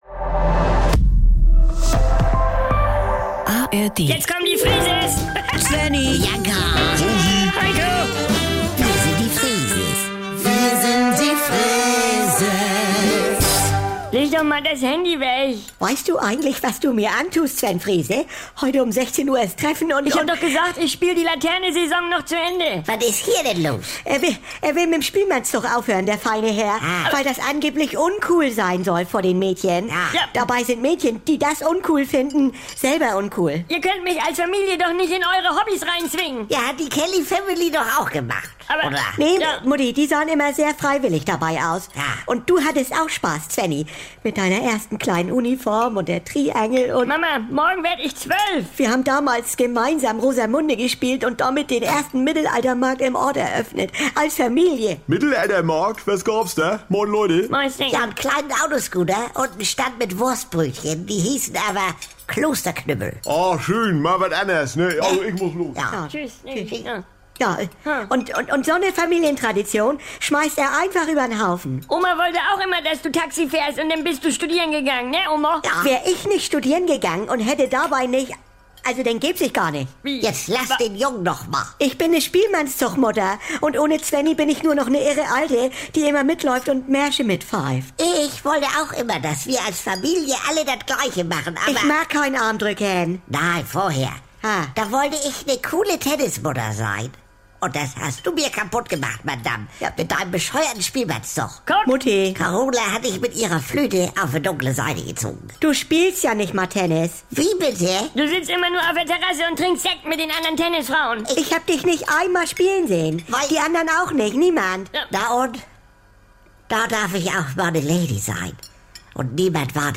… continue reading 1871 afleveringen # Saubere Komödien # NDR 2 # Komödie # Unterhaltung